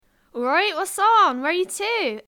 Examples of Cornish English
//ɔl ɻɑɪt/wɒts ɒn/wɛɻ jə tuː//